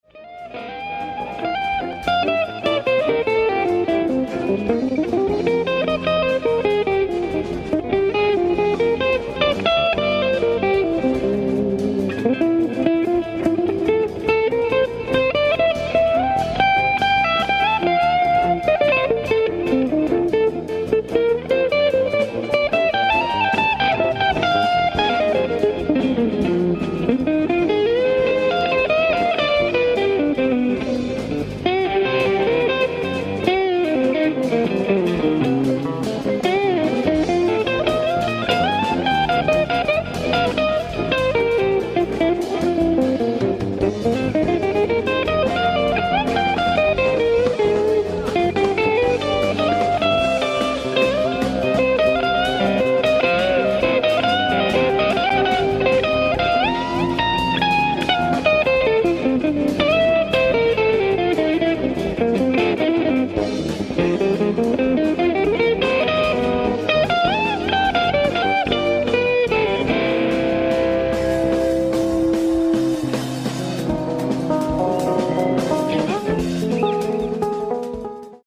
ライブ・アット・サンタテレサ・ジャズ、エスピリト・サント、ブラジル 06/13/2025
ステレオ・サウンドボード音源！！
※試聴用に実際より音質を落としています。